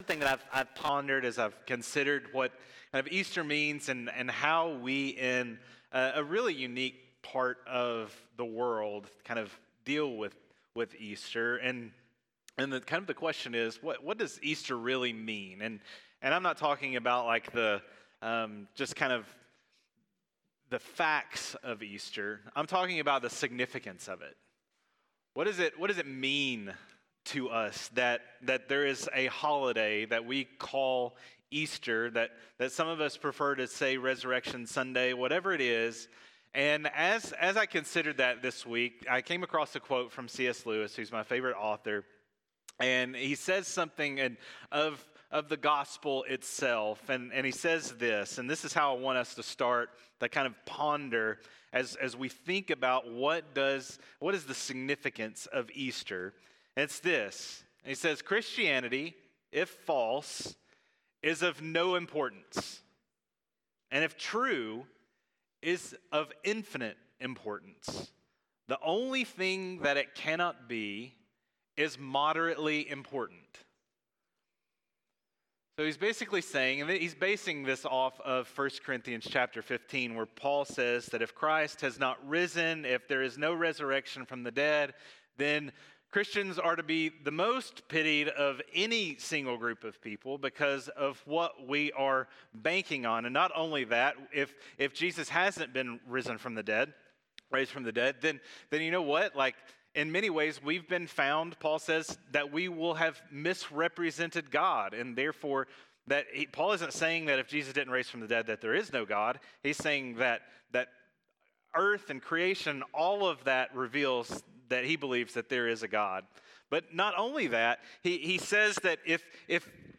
Easter-Sermon.mp3